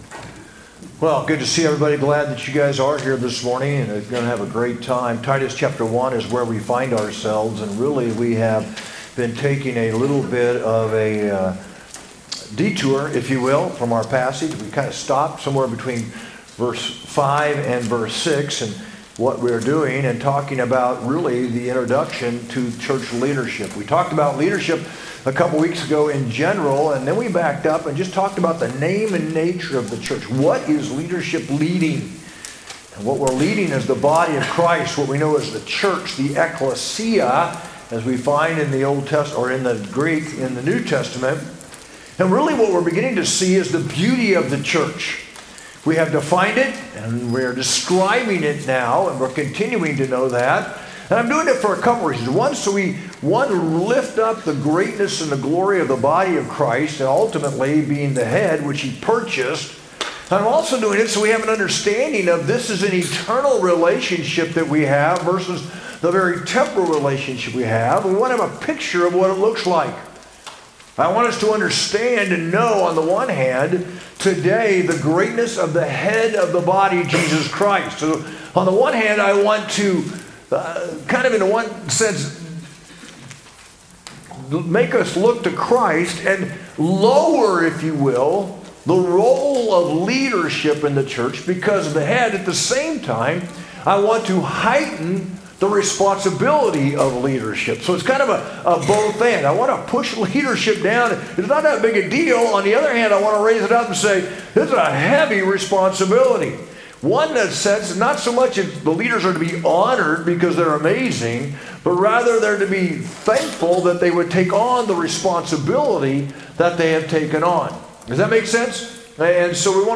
sermon-3-30-25.mp3